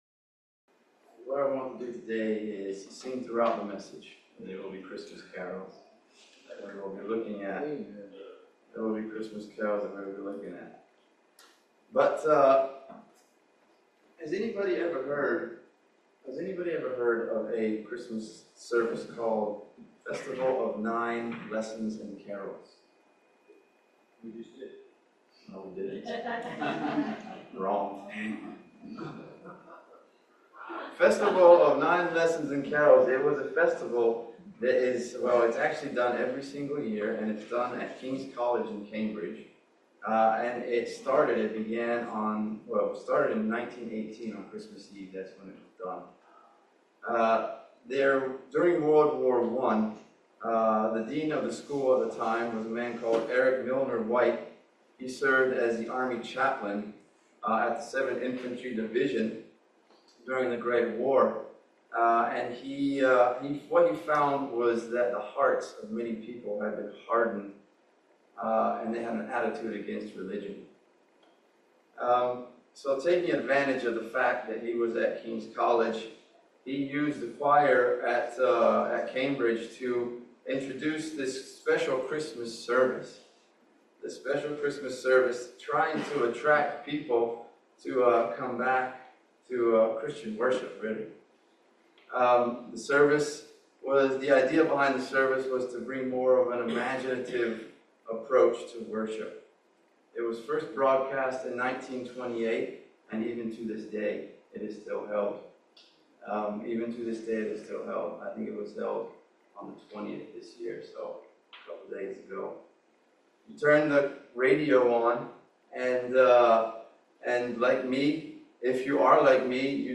A Festival of 9 Lessons & Carols